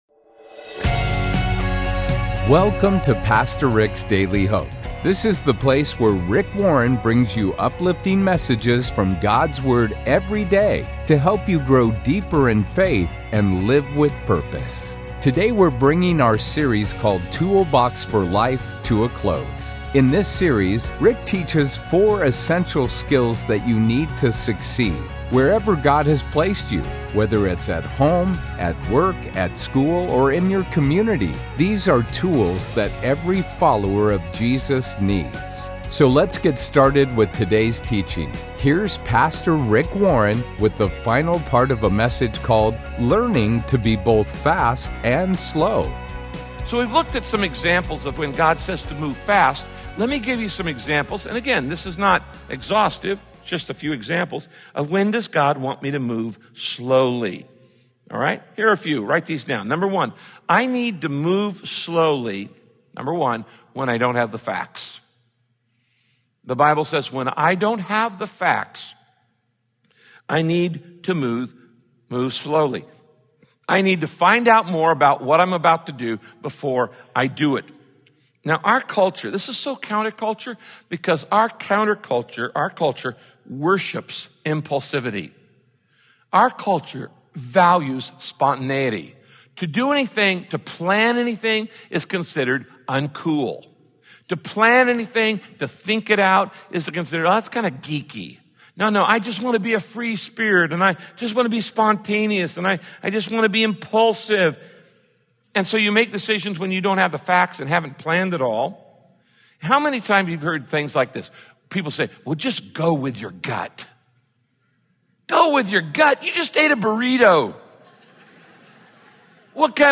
In this message, Pastor Rick shares how God sometimes asks you to move slowly, such as when you don't have all the facts, when you're hurt or angry, when you're…